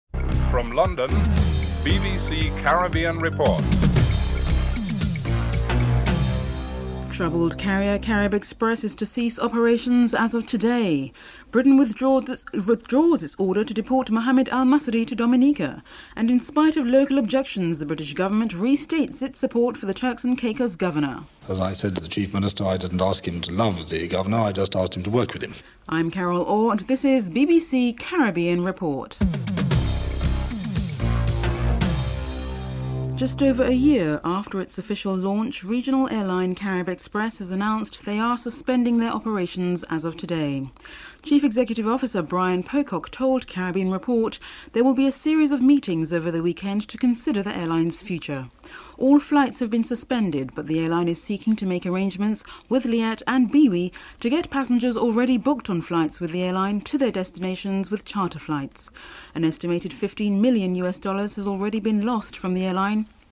1. Headlines (00:00-00:31)
3. Britain withdraws its order to deport Mohammad Al-Massari to Dominica. Saudi dissident Mohammad Al-Massari, Opposition Leader Rosie Douglas and Home Office Minister Anne Widdecombe are interviewed (05:01-06:51)
4. Inspite of local objections the British government restates its support for the Turks and Caicos governor. British Minister Sir Nicholas Bonsor is interviewed (06:52-10:51)